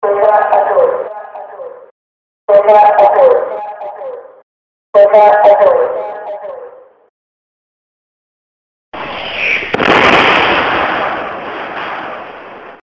למי יש את האזעקה צבע אדום תודה מראש